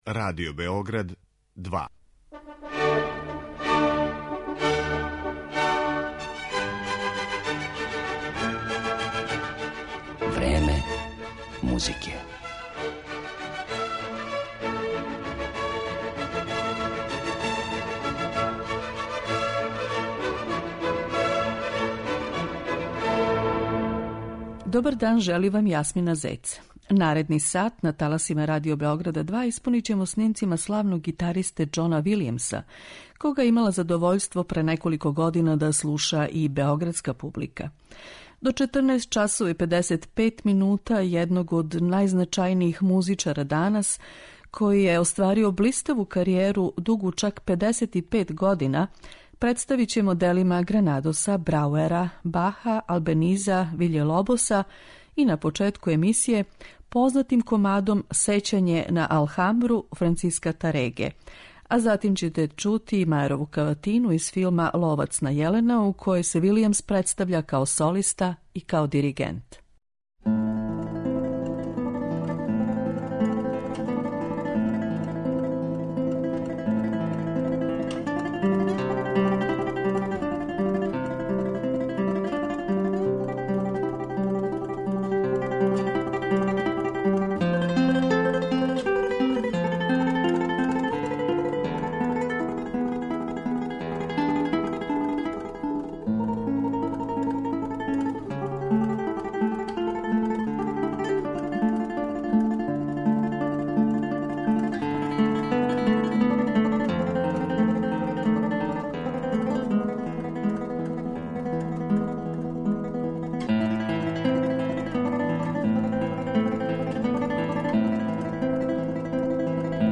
стандарног гитаристичког репертоара